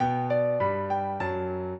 piano
minuet1-3.wav